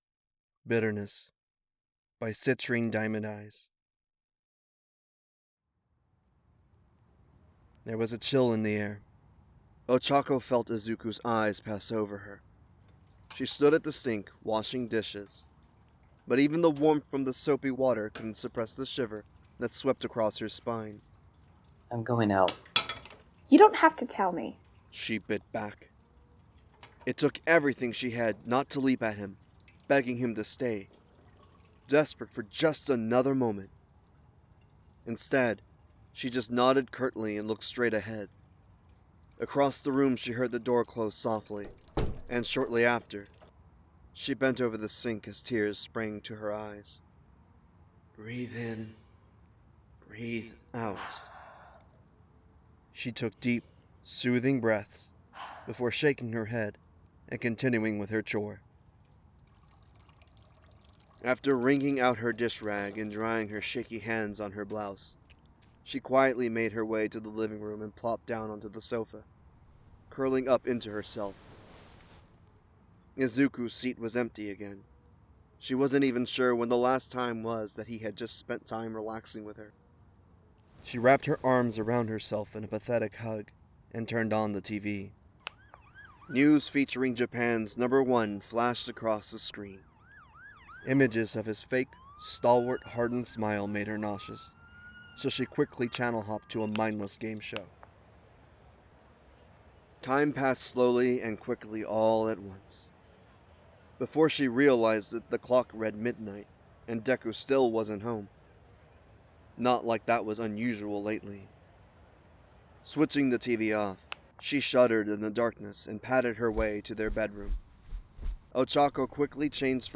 Bitterness | Podfic
Voice of Ochako Uraraka
Voice of Izuku Midoriya
CountrySideWinterEvening02.wav